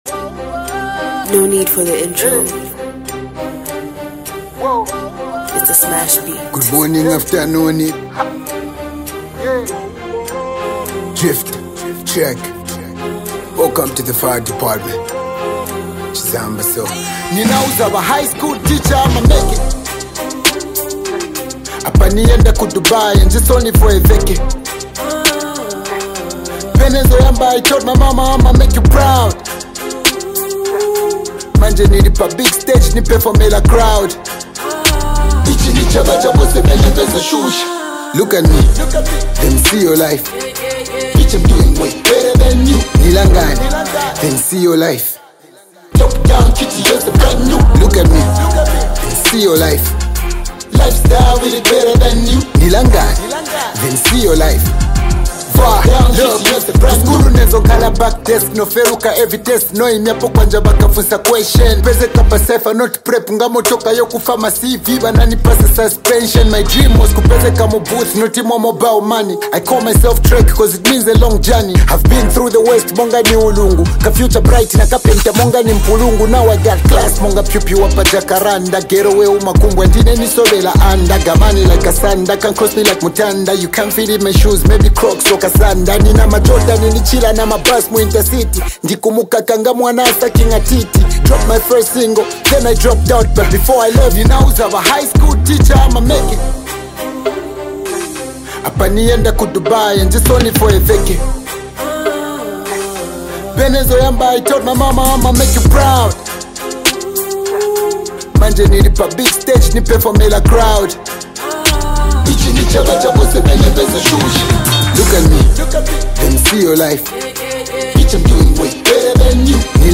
" featuring two of the biggest names in Zambian hip-hop
a blend of infectious beats and thought-provoking lyrics